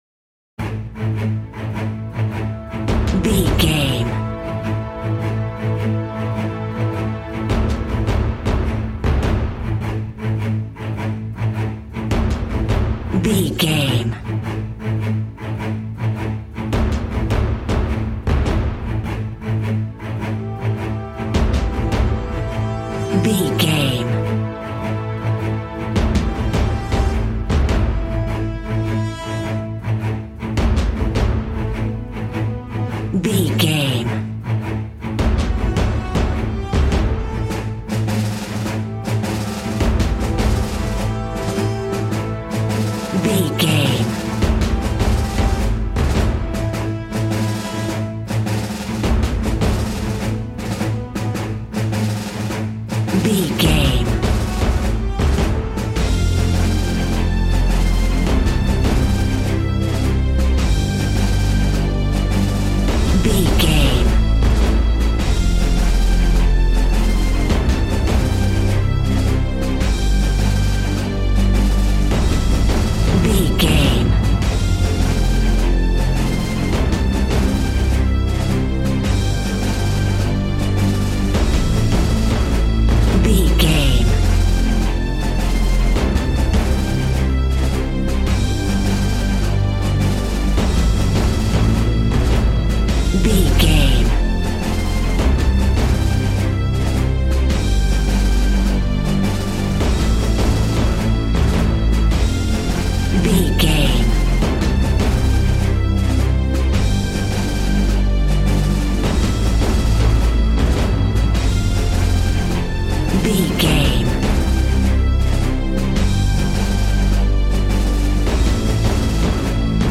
Aeolian/Minor
dramatic
strings
percussion
synthesiser
brass
violin
cello
double bass